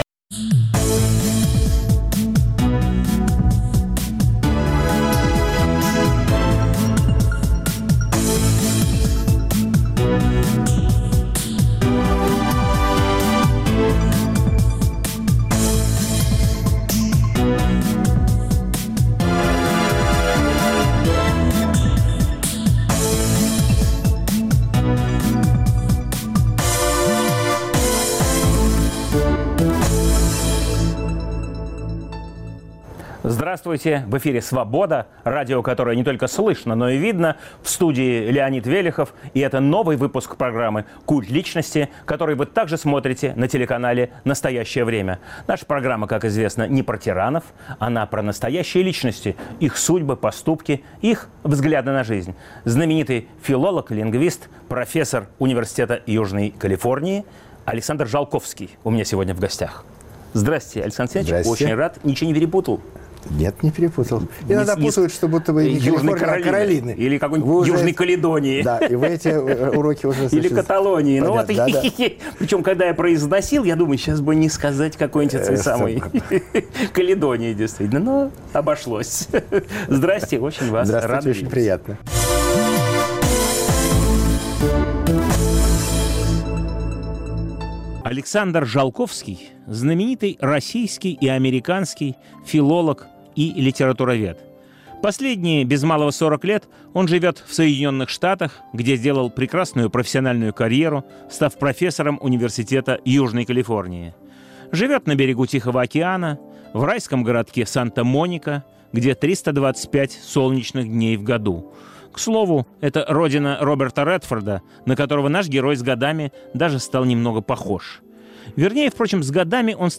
Новый выпуск программы о настоящих личностях, их судьбах, поступках и взглядах на жизнь. В студии профессор Университета Южной Калифорнии Александр Жолковский.